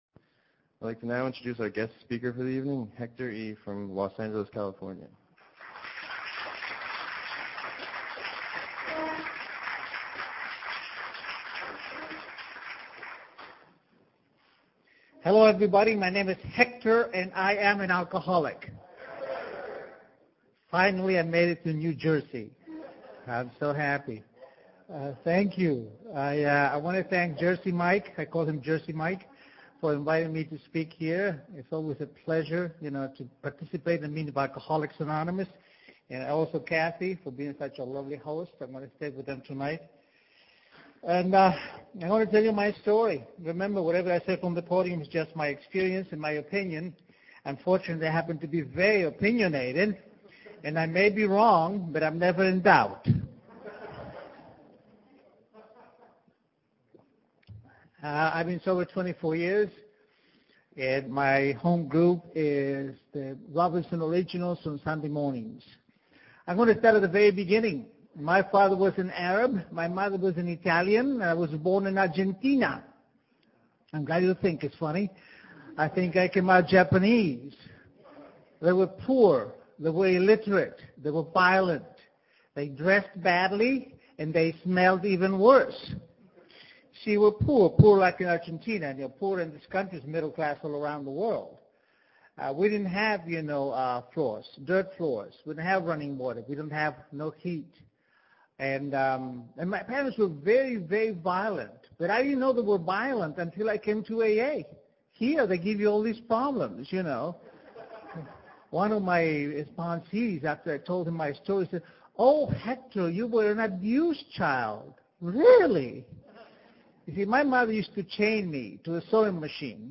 Speaker Tape